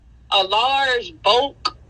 a large bhoke Meme Sound Effect